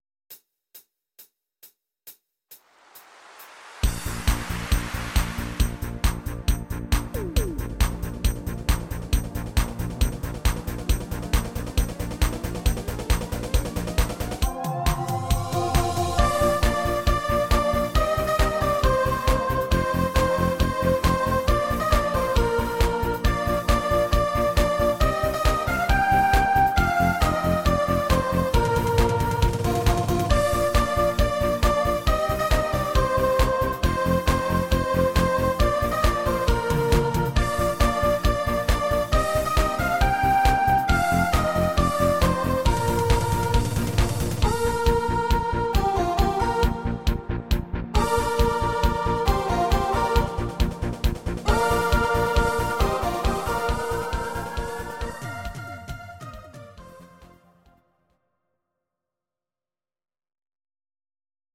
Audio Recordings based on Midi-files
Pop, German, Medleys